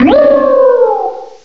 cry_not_alomomola.aif